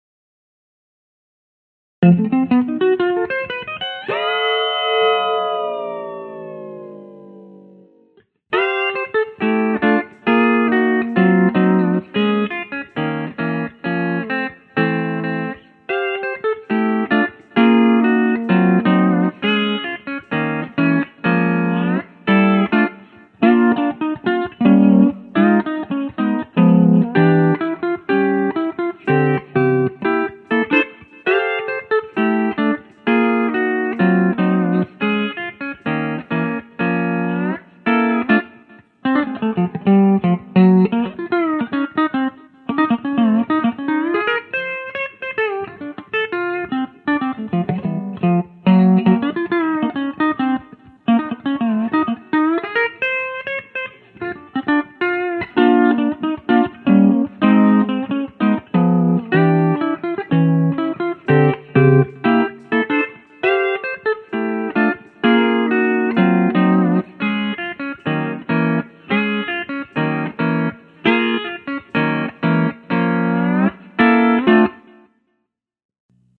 * is voiced perfectly for the cleanest, purest steel guitar sound I have ever heard
I just turned it on, stuck a mic in front of it and recorded a few soundbytes -- here are some examples